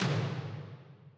b_perc_v100l8o7d.ogg